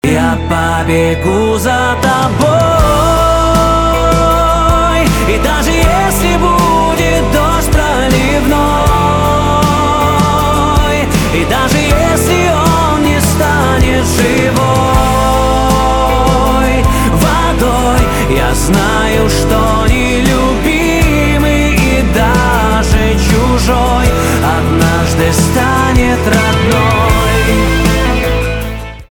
поп
чувственные , гитара , барабаны